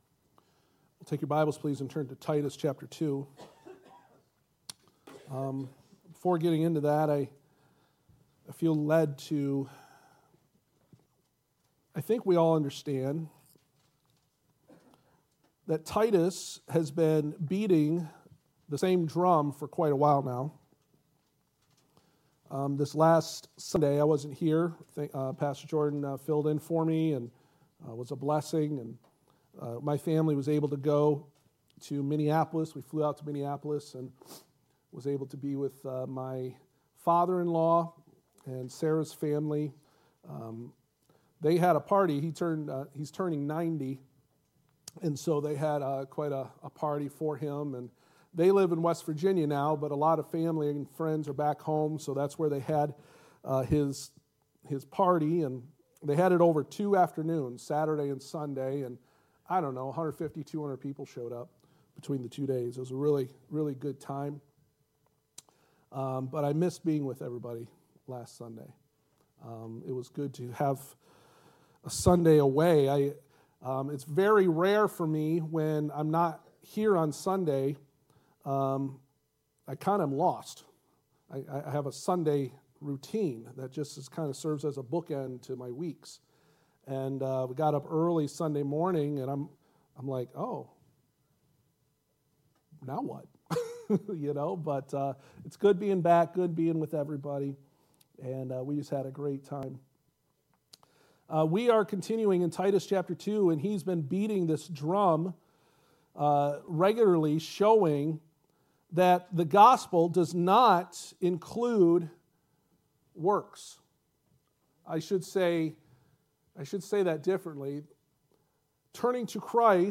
Passage: Titus 2 Service Type: Sunday Morning « Significant Importance of Practicing Godly Qualities